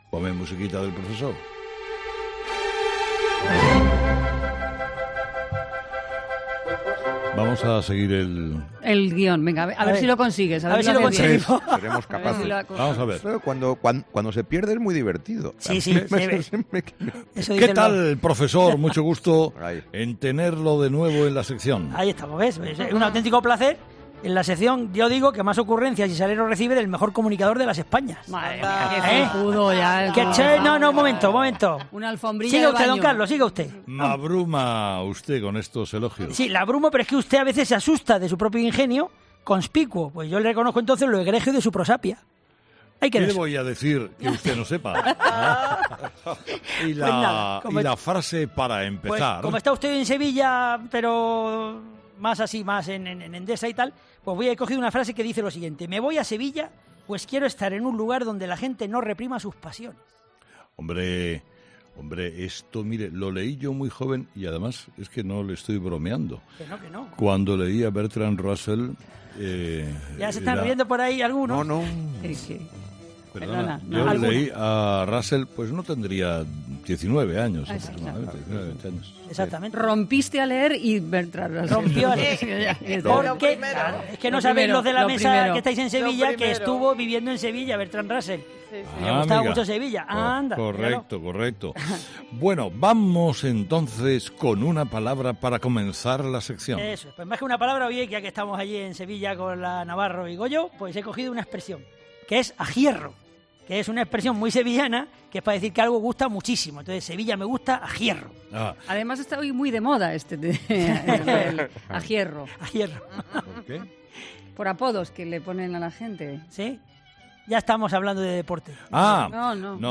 El programa de este martes se ha hecho desde Sevilla.